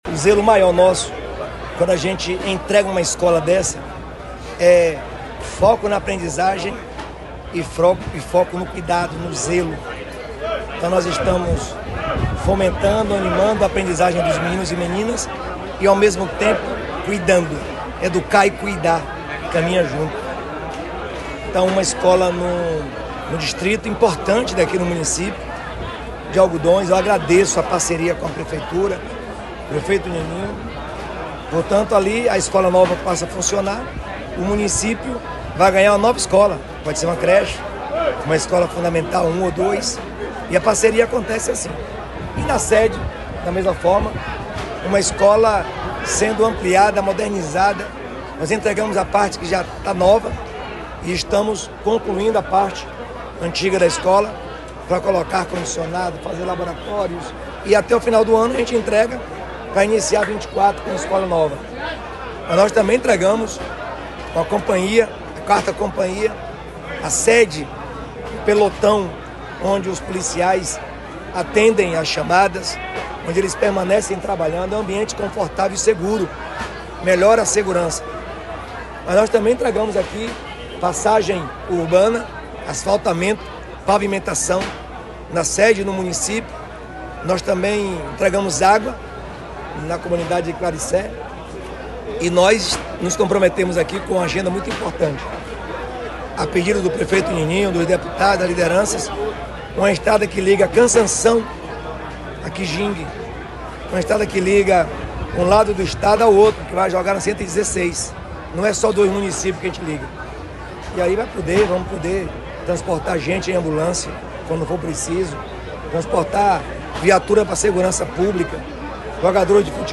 🎙Governador Jerônimo Rodrigues durante a inauguração de escolas